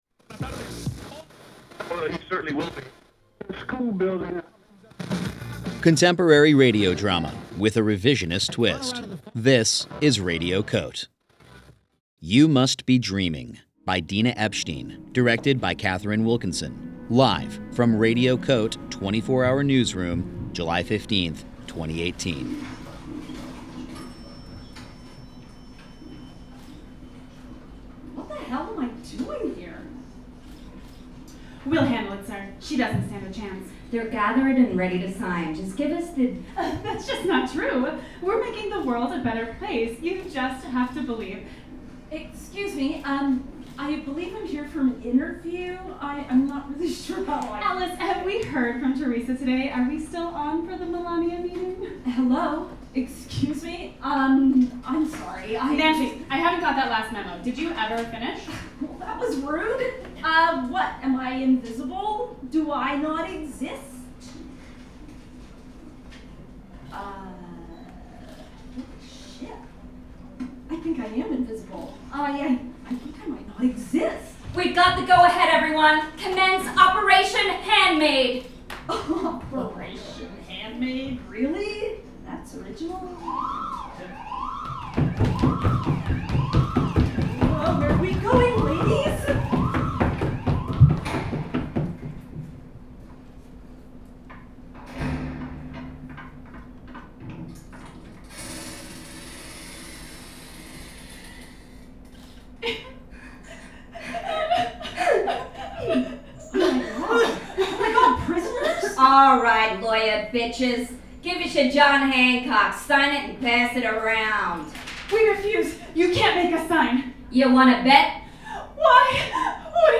performed live at UNDER St. Mark’s for Radio COTE: 24-hour Newsroom, July 15, 2018